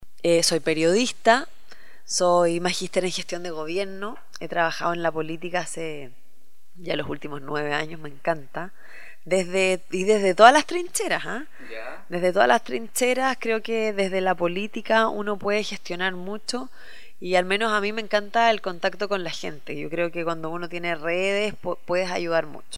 Para dar a inicio a este proyecto nos dirigimos hasta la Seremi de gobierno de la Araucanía y nos entrevistamos con Pía Bersezio y le solicitamos que se presentara.